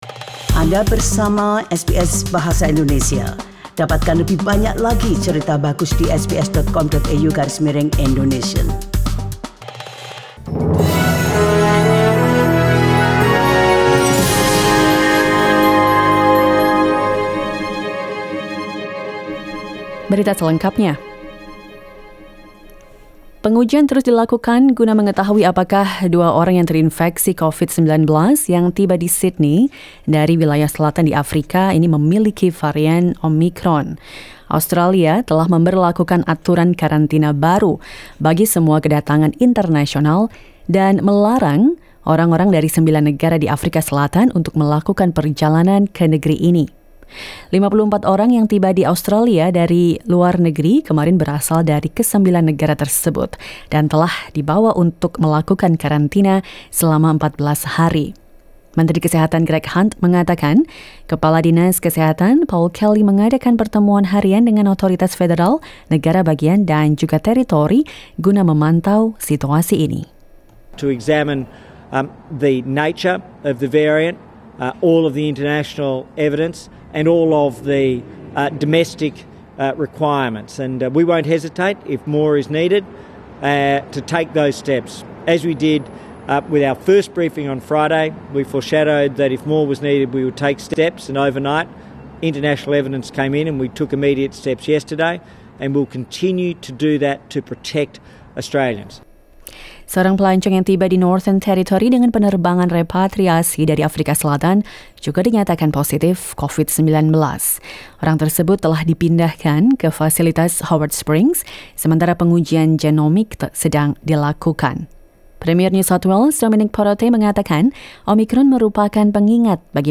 SBS Radio news in Bahasa Indonesia - 28 November 2021